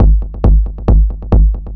K-5 Kick.wav